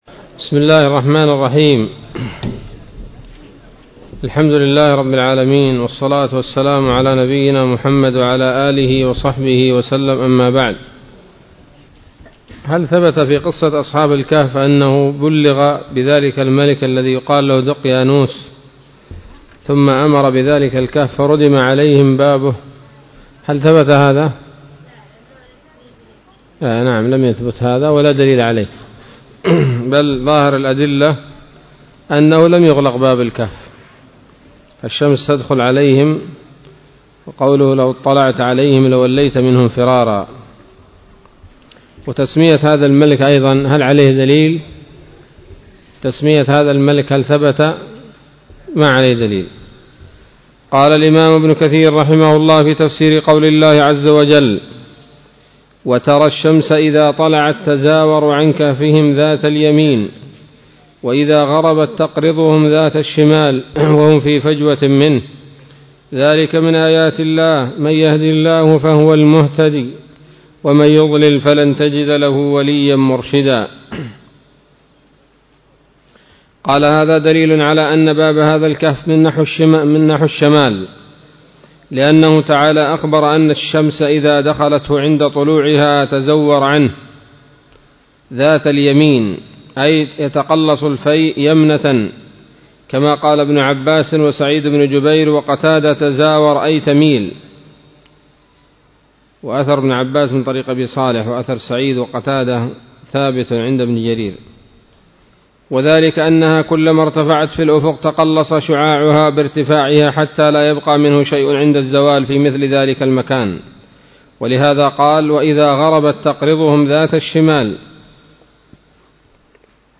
الدرس الرابع من سورة الكهف من تفسير ابن كثير رحمه الله تعالى